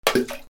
/ M｜他分類 / L30 ｜水音-その他
水にゴム足を投げ込む
『チャポン』